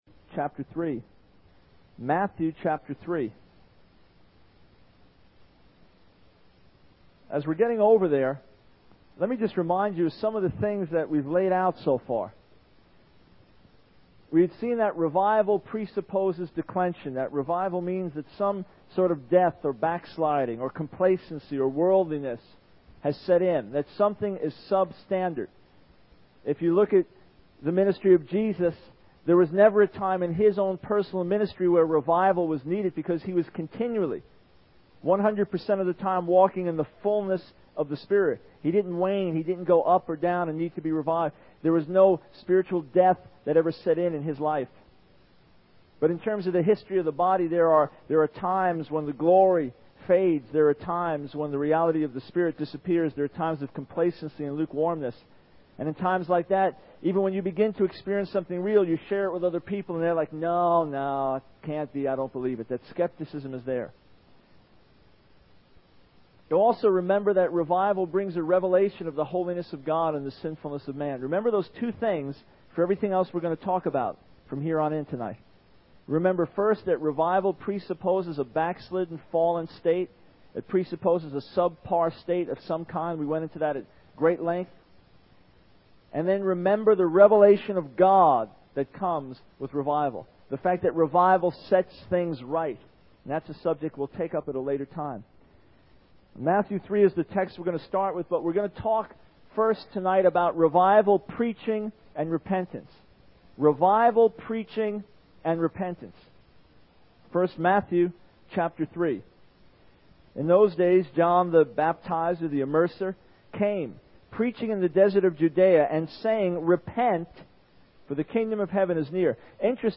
In this sermon, the preacher discusses the topic of revival preaching and repentance, using Matthew 3 as the starting point. He emphasizes that revival is needed when there is a decline or complacency in the spiritual life.